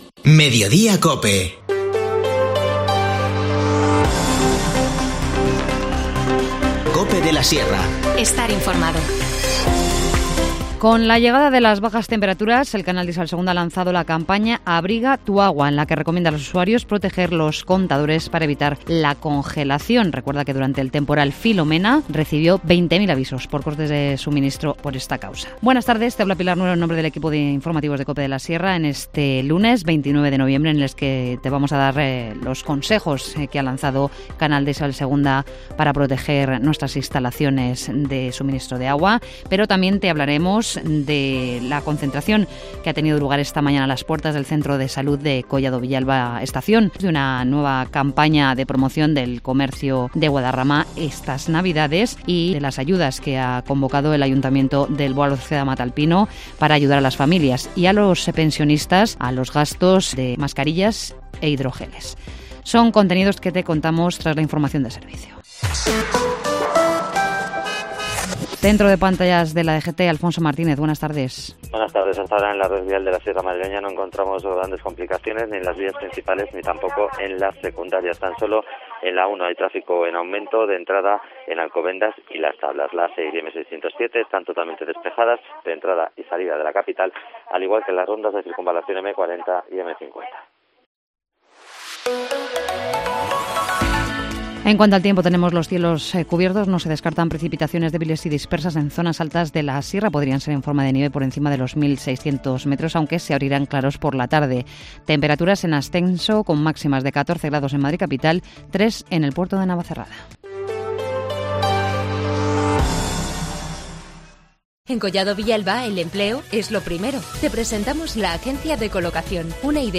Informativo Mediodía 29 noviembre